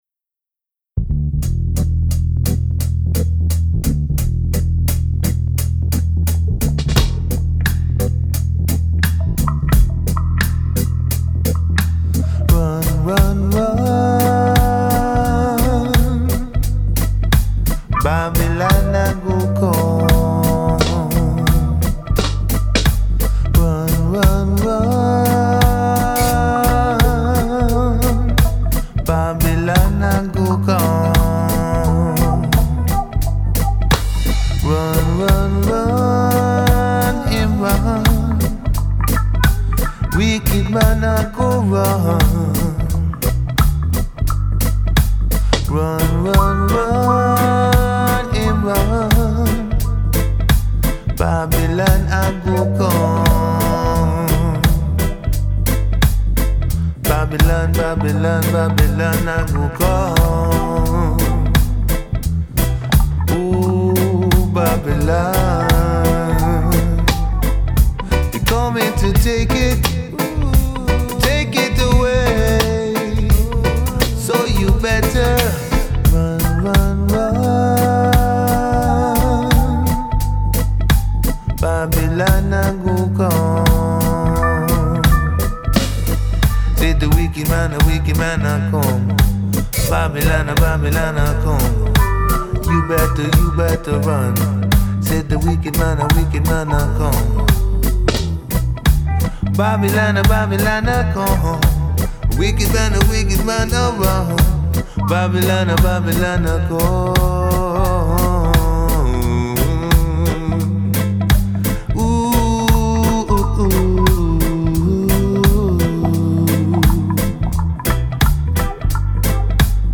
Please for your feedback on this reggae mix
A really cool laid back reggae mix. please for your feedback